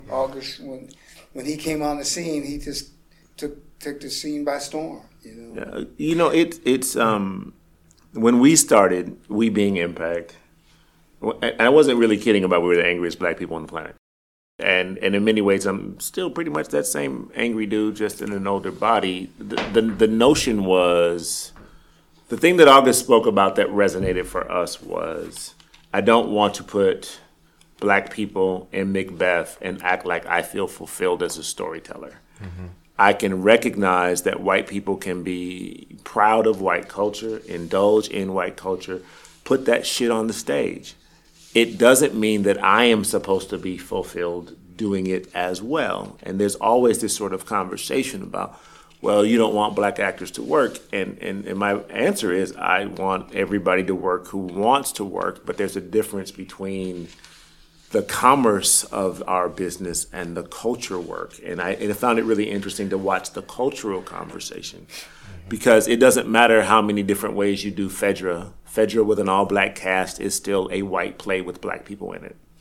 Perhaps the most enduring of these resulted in the “open feud” between Wilson and Robert Brustein. Join us as we bring together a multi-generational, multi-racial collection of artists to discuss the legacy of August Wilson, the arts and arguments that continue to fuel this debate, and the station of Black voices on the contemporary American stage.